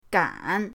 gan3.mp3